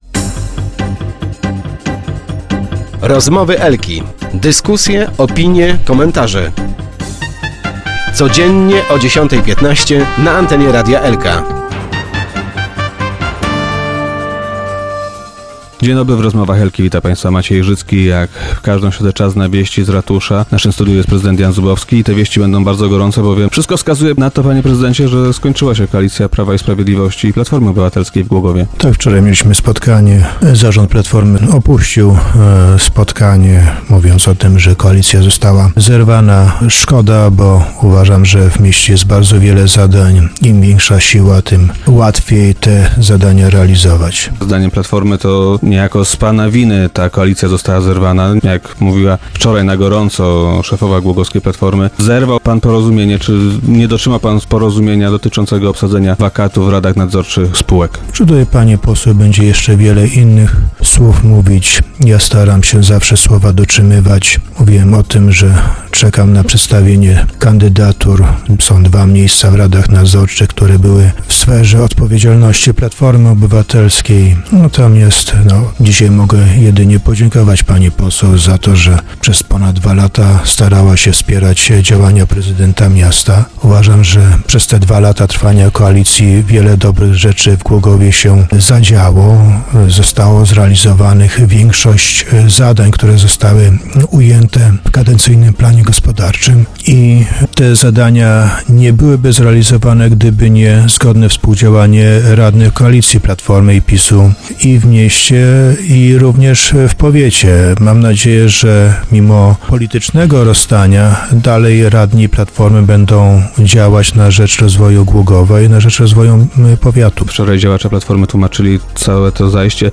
- Zarząd Platformy opuścił wczorajsze spotkanie twierdząc, że koalicja została zerwana. Szkoda bo w mieście jest wiele zadań do zrealizowania, a uważam, że im większa siła, tym łatwiej się z nimi uporać - powiedział prezydent Jan Zubowski, dzisiejszy gość Rozmów Elki.